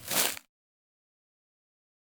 footsteps-single-outdoors-002-04.ogg